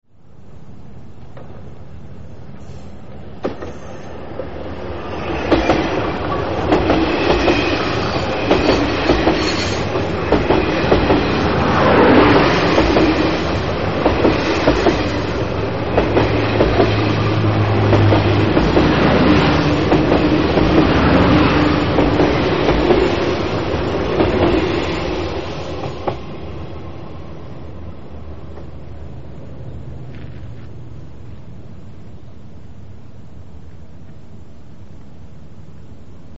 呉線キハ５８系急行「出島」の回送列車。小屋浦進入
（通過音）MP-3　モノラル　142KB　36秒
離合のため小屋浦駅に停車する時の音です。
１０両編成の堂々としたキハ５８系の通過音、低速ながら、今となるとジッと聞いてしまいます。